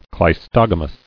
[cleis·tog·a·mous]